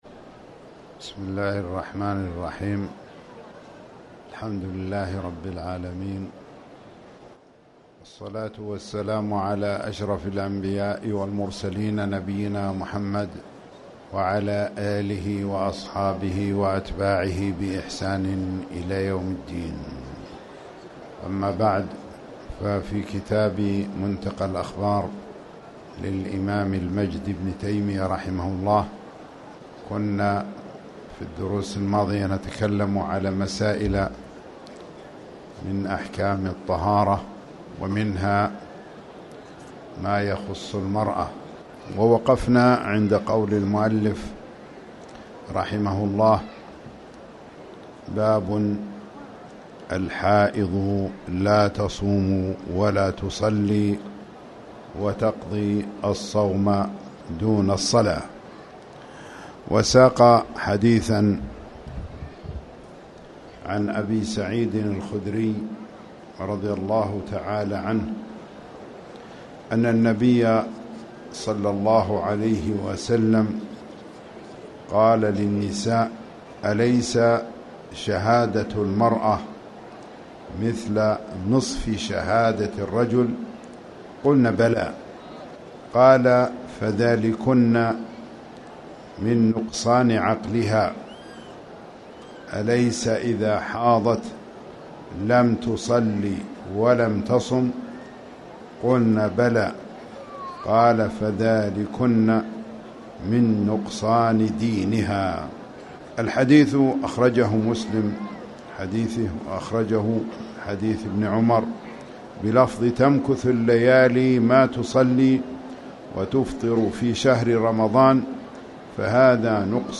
تاريخ النشر ١ شعبان ١٤٣٩ هـ المكان: المسجد الحرام الشيخ